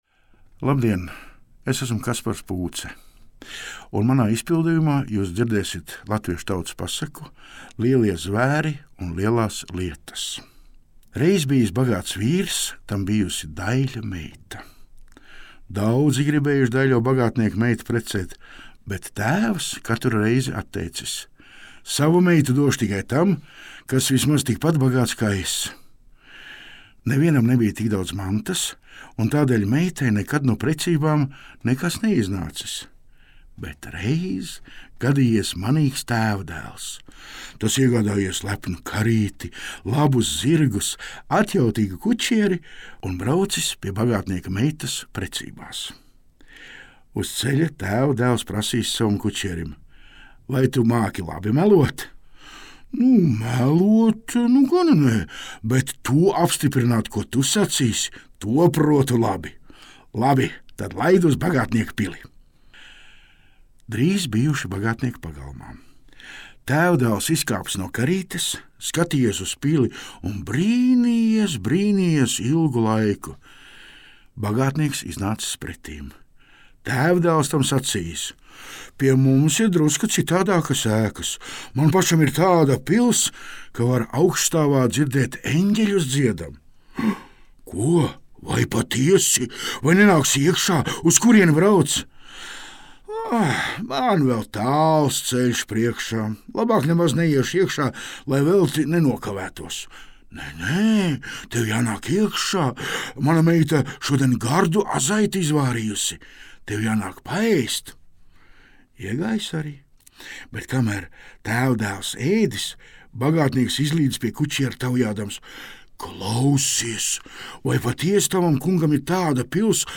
Teicējs